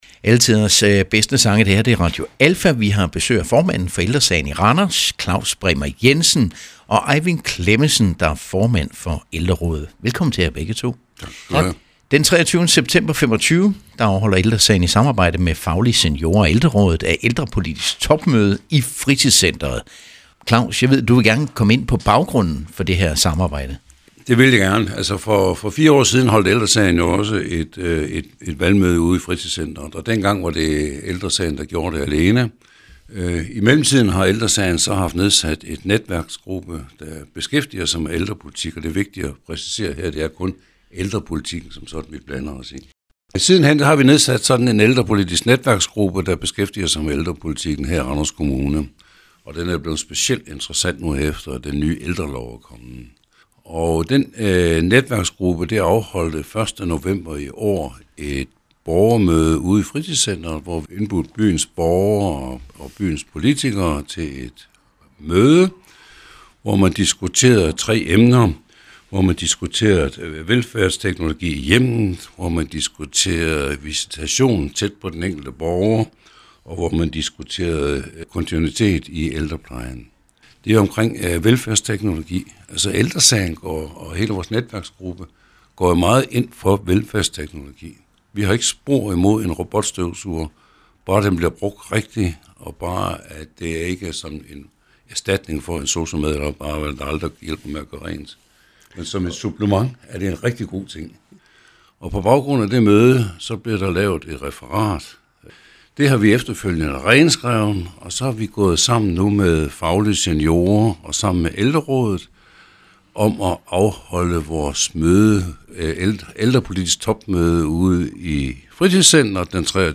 Interview om Kommunalvalg 2025